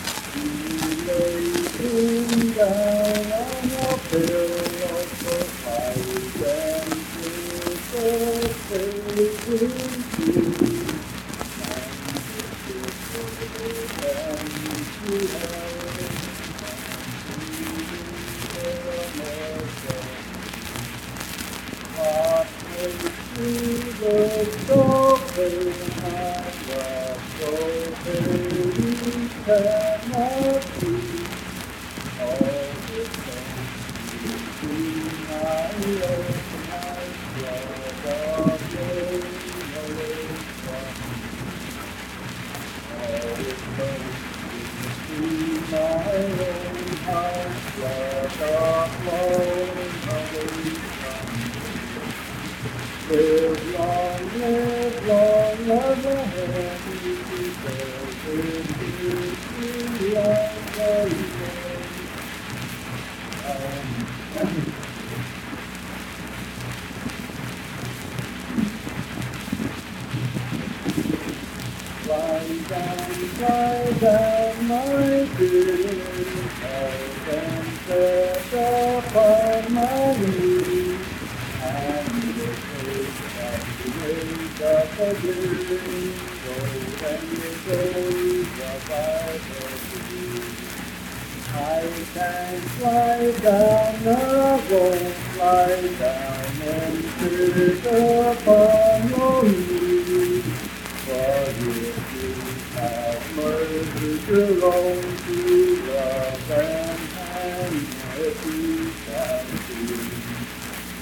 Unaccompanied vocal music
Voice (sung)
Randolph County (W. Va.)